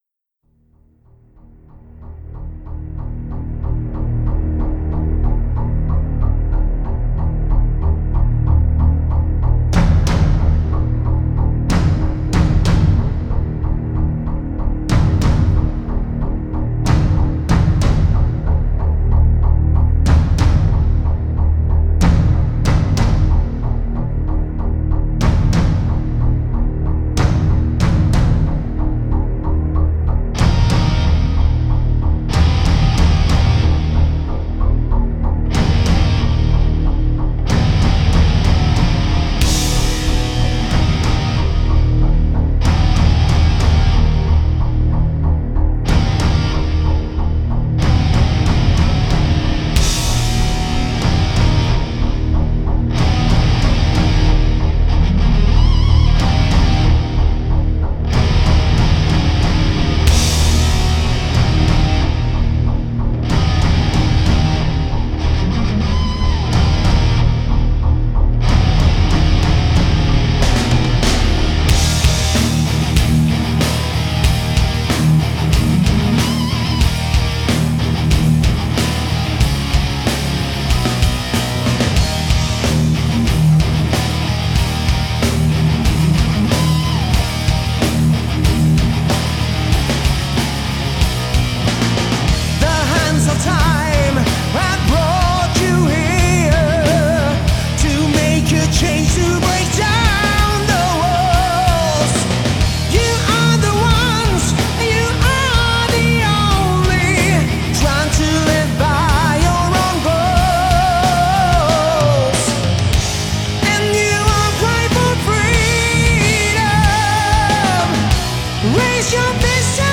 kul вокал классный и музунчик!